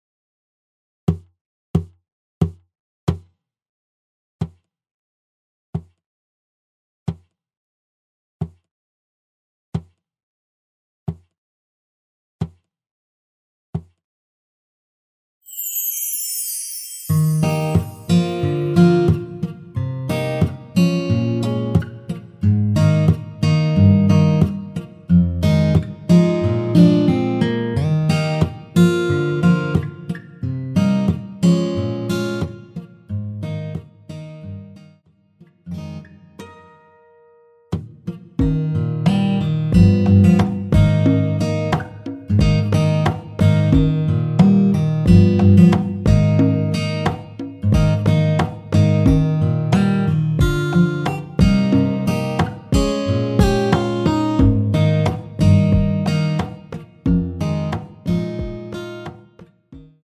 전주 없이 무반주로 노래가 시작 하는 곡이라서
노래 들어가기 쉽게 전주 1마디 넣었으며
노래 시작 앞부분이 무반주라서 기타 바디 어택으로
원키에서(-1)내린 MR입니다.
Eb
앞부분30초, 뒷부분30초씩 편집해서 올려 드리고 있습니다.